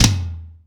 ROOM TOM3B.wav